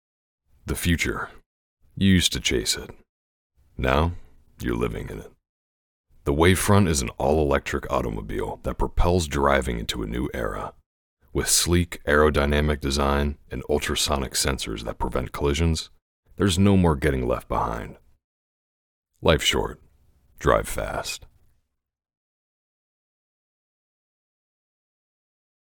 Television Car Commercial Demo
General American
Young Adult
Middle Aged